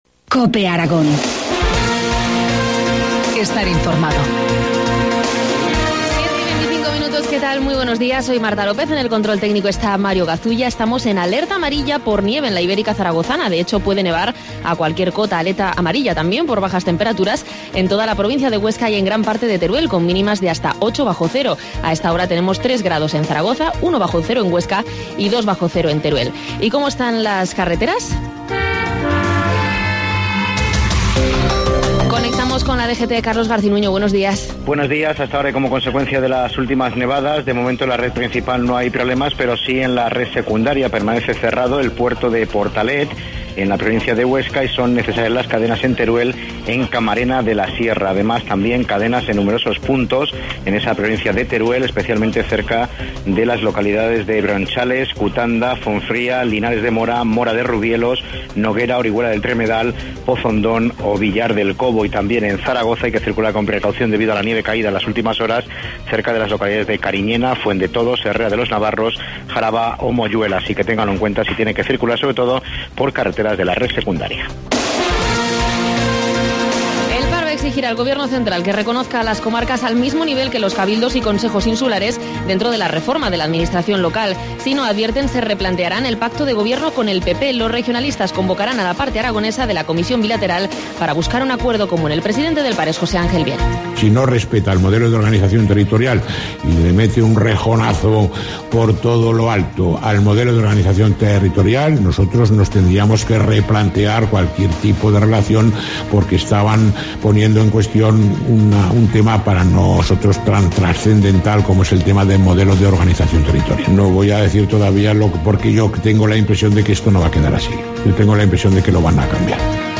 Informativo matinal, martes 26 de febrero, 7.25 horas